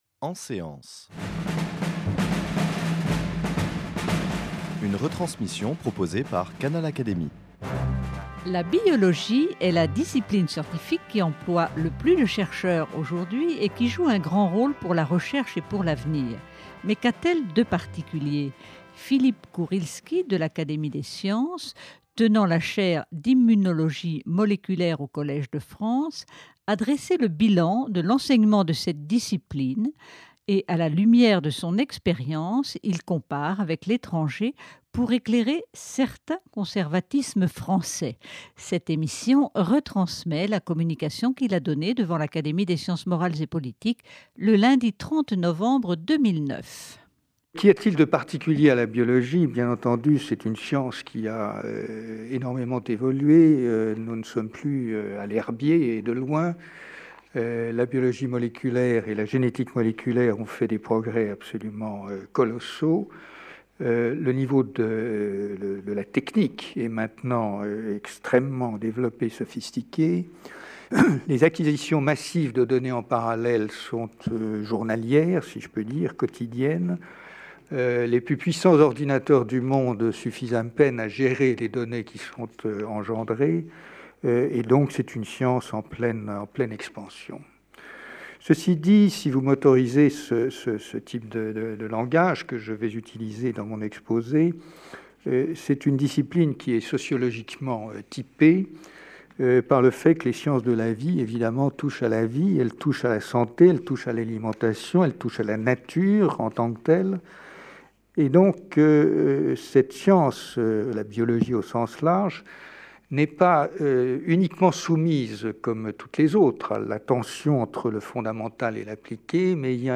C'est par cette idée que Philippe Kourilsky a commencé son exposé devant les membres de l'Académie des sciences morales et politiques réunis en séance le lundi 30 novembre.